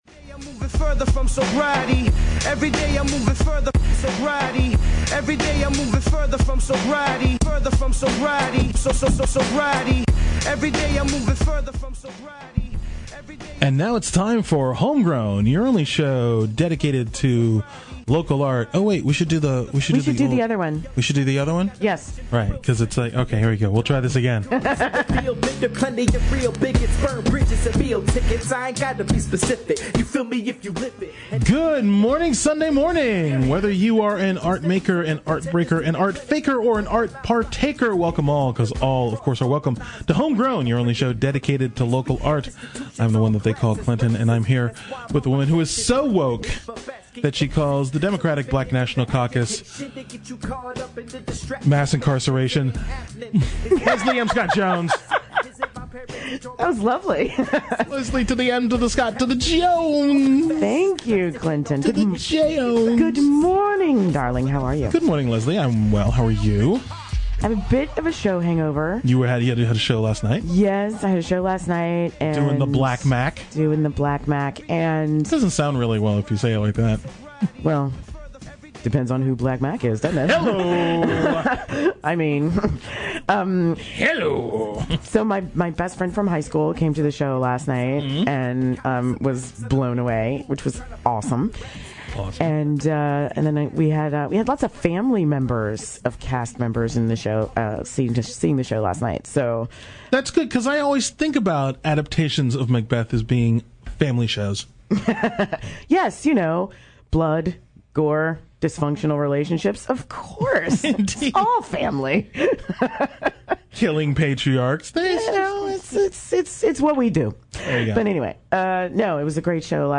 He also performs a song from the album live for us. We’ve got two great local artists talking about their art.